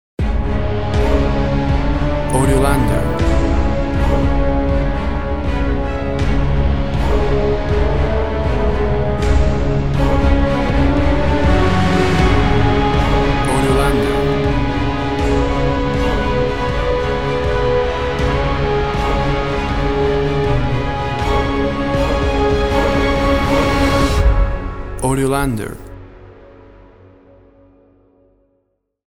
Powerful song with symphony orchestra. Epic music.
Tempo (BPM) 80